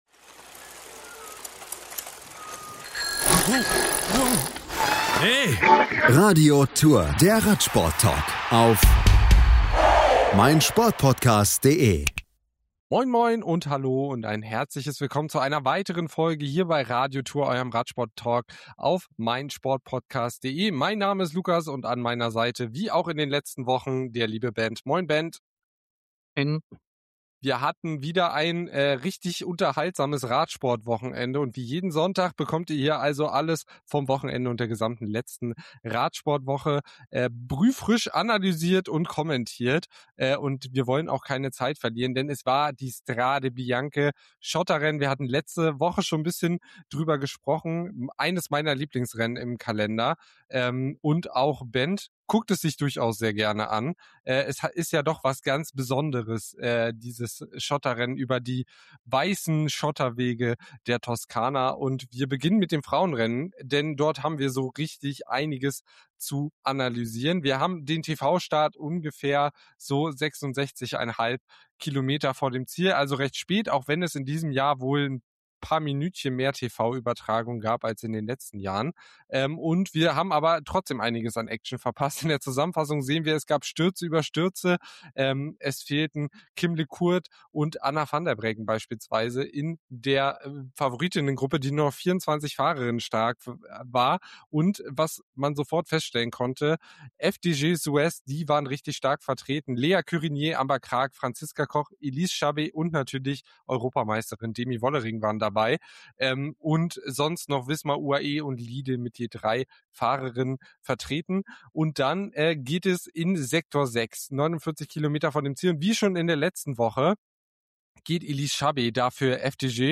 Die beiden Hosts sprechen außerdem über den Auftakt von Paris-Nizza und den weiteren Ergebnissen der vergangenen Woche. Im letzten Teil werfen sie dann einen tieferen Blick auf News aus dem Frauenradsport, die vor allem den Kopf schütteln lassen.